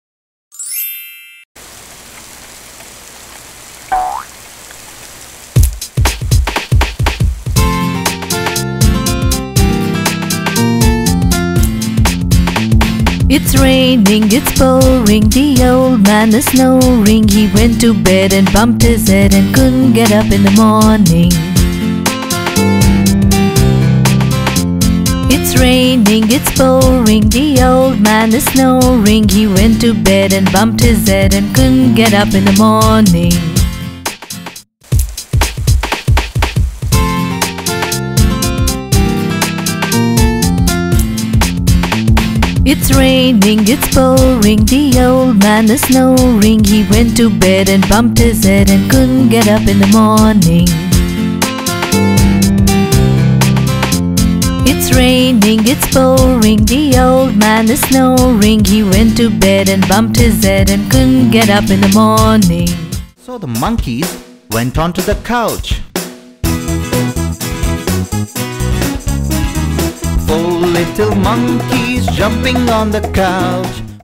Audio: It's Raining, It's Pouring Nursery Rhyme